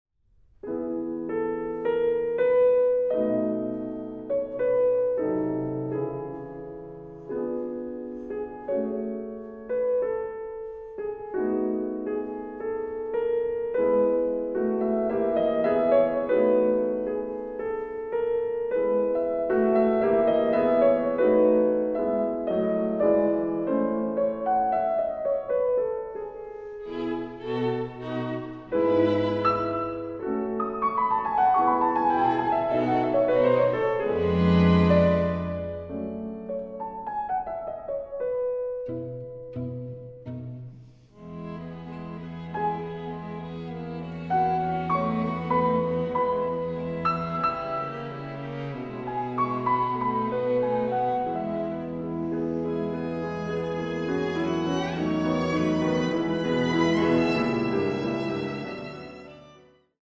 Piano quartet